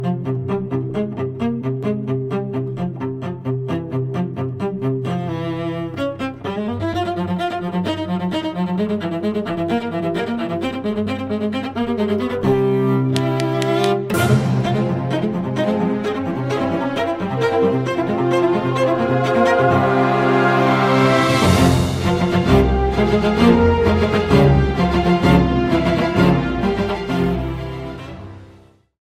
Le violoncelle est mis à l'honneur !